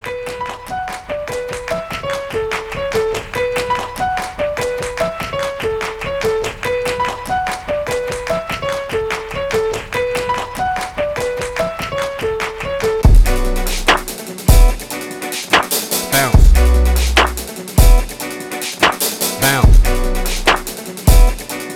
Гучні мелодії на дзвінок